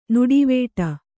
♪ nuḍivēṭa